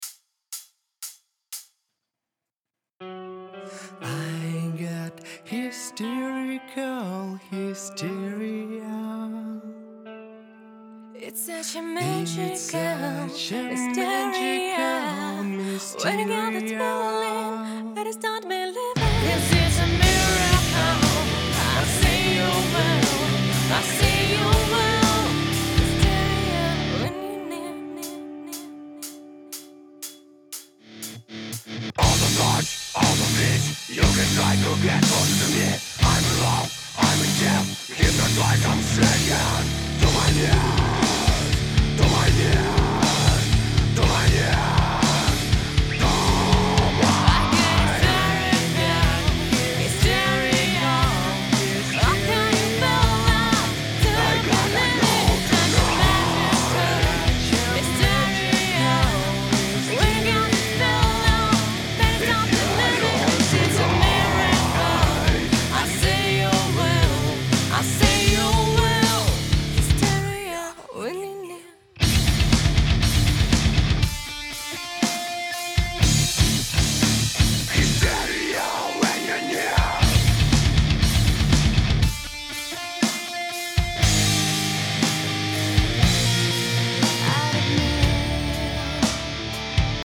Прошу оценить сведение альтернативы.